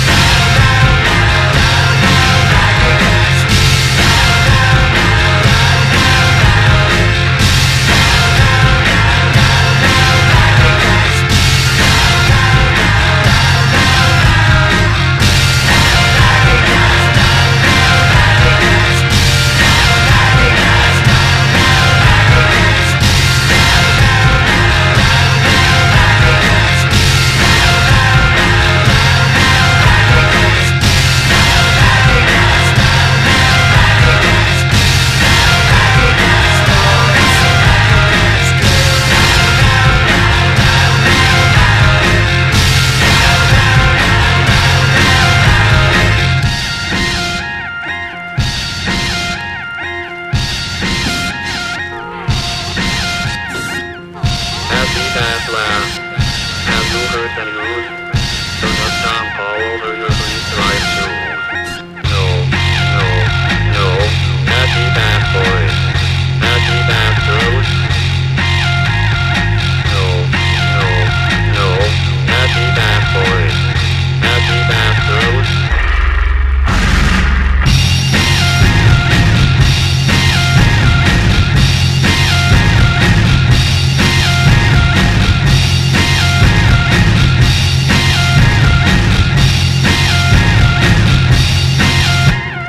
JAPANESE HOUSE
CLUB JAZZ
クラブ・ジャズ好きも必聴の生音ハウス！